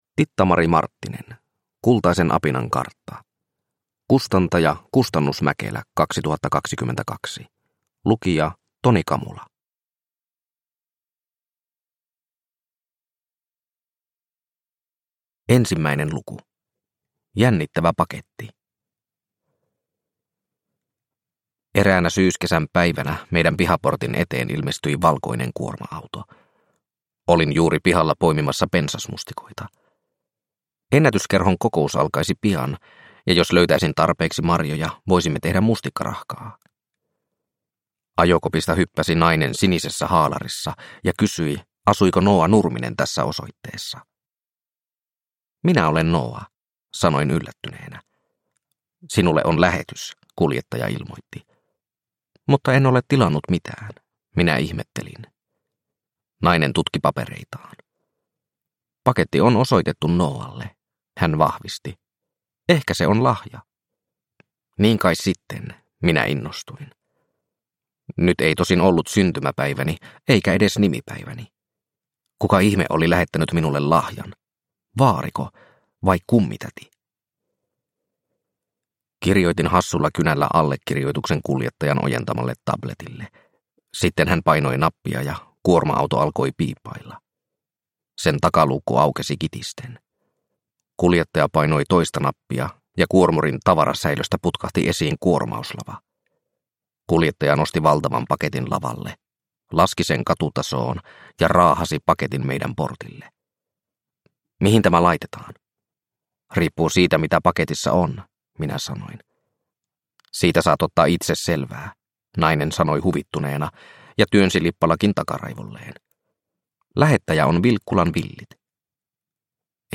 Kultaisen apinan kartta – Ljudbok – Laddas ner